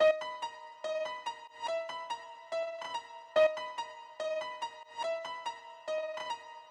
可怕的陷阱钢琴 143 bpm
Tag: 143 bpm Trap Loops Piano Loops 1.13 MB wav Key : D